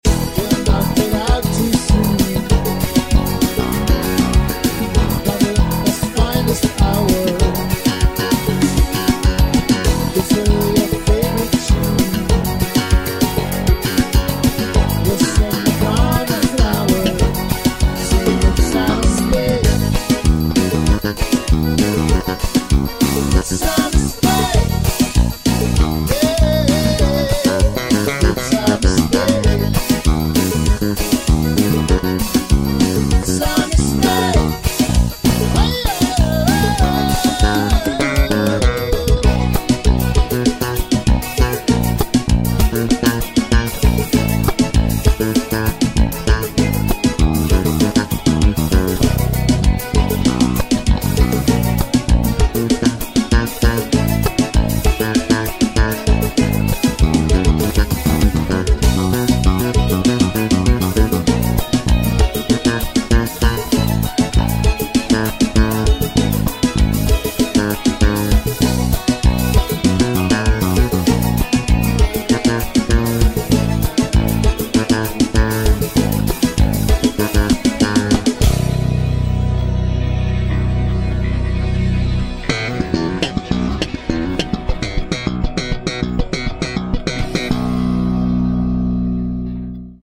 pegada nordestina